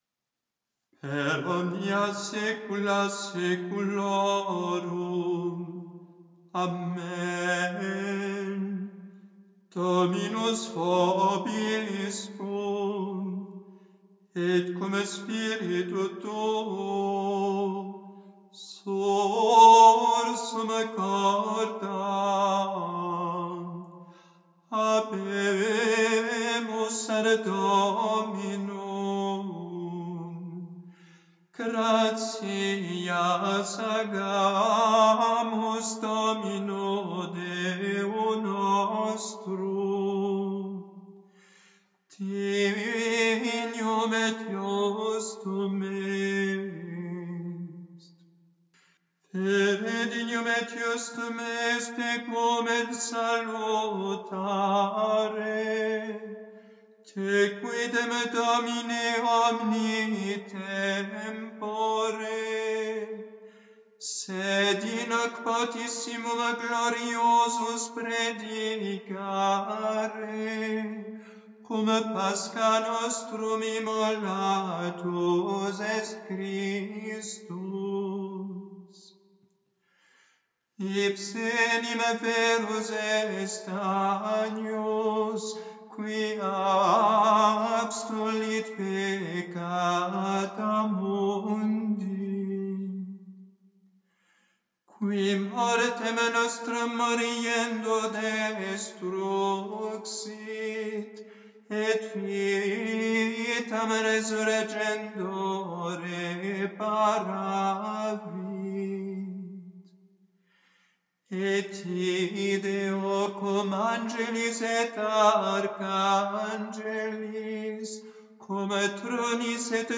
Conclusion de la secr�te et pr�face de P�ques La nuit pascale le pr�tre dit : ... in hoc potissimum nocte gloriosus...